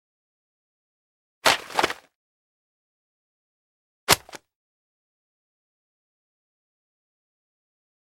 Звук падения книги